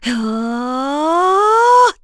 Laudia-Vox_Casting3_kr.wav